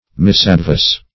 Misadvice \Mis`ad*vice"\